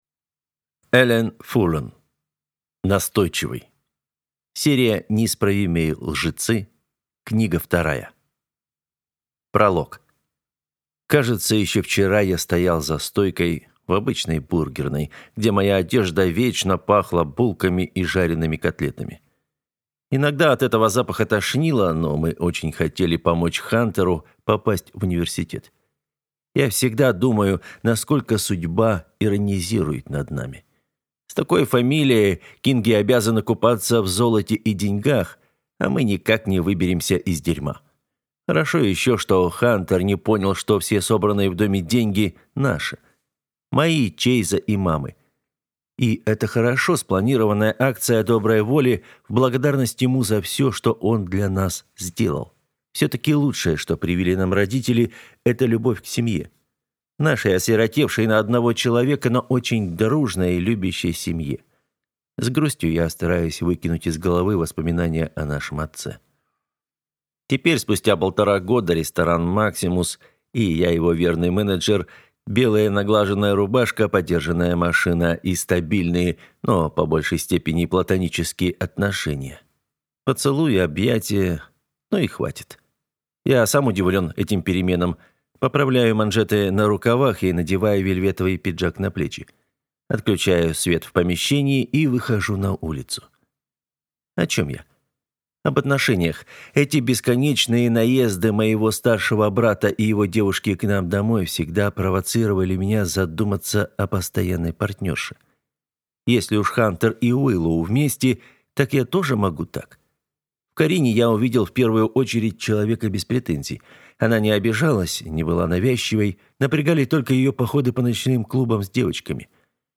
Aудиокнига Настойчивый